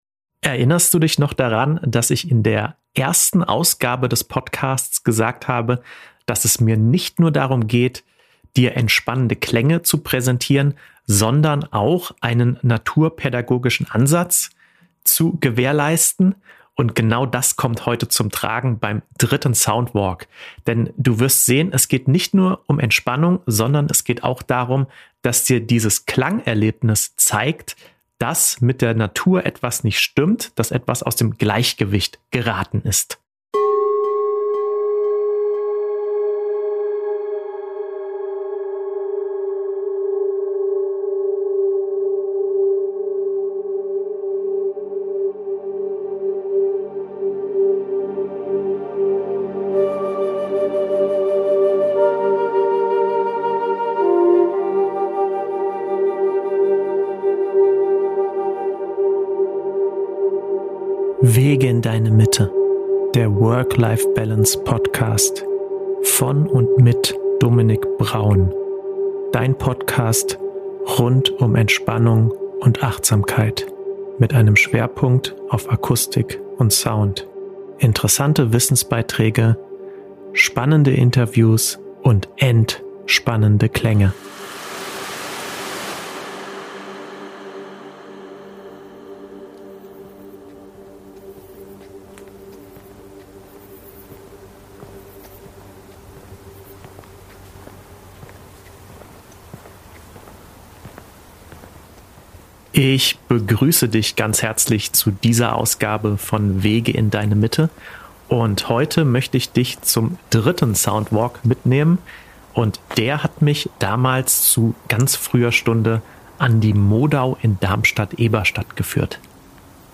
In der 19. Episode meines Podcasts nehme ich Dich mit auf den dritten Soundwalk, der Dich ans Wasser führt – genauer gesagt an die Modau bei Darmstadt-Eberstadt. Der Weg führt uns an einem trockenen Sommermorgen erst über die Felder und dann an das strömende Wasser.